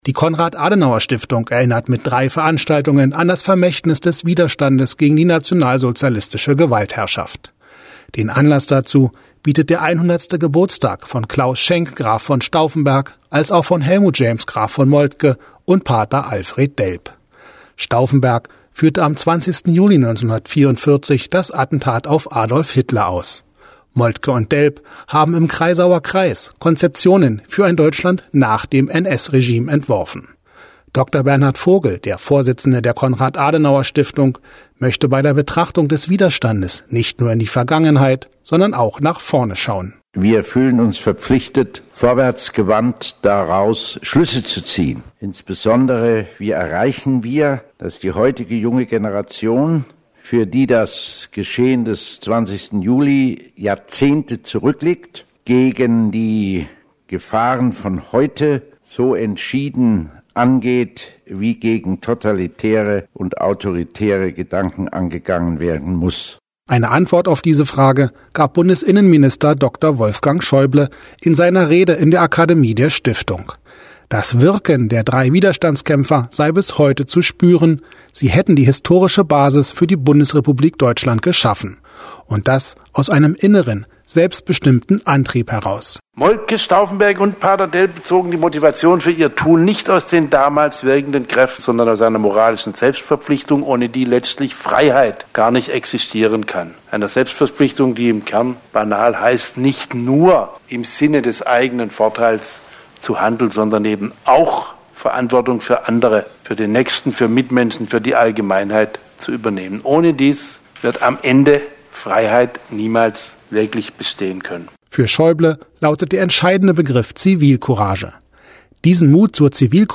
Eine Antwort auf diese Frage gab Bundesinnenminister Dr. Wolfgang Schäuble in seiner Rede in der Akademie der Stiftung.